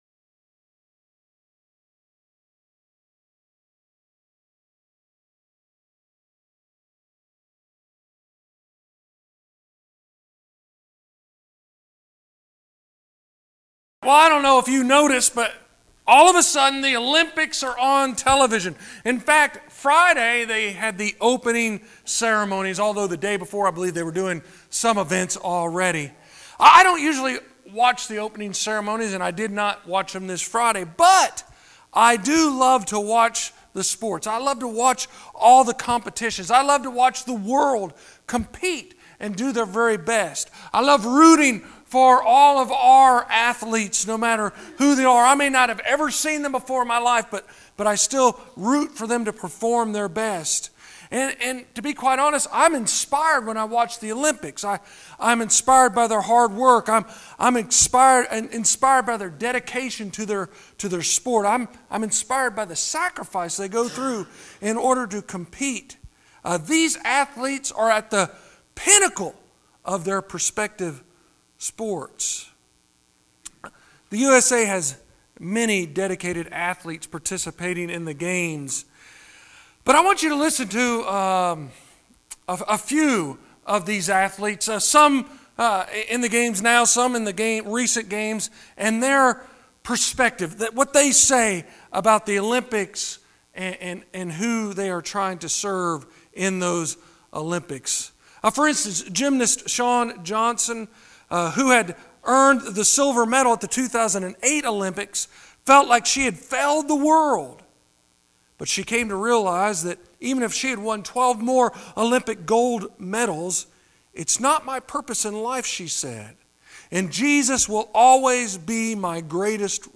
29.51 Sermons in this series The Cross Is Our Victory!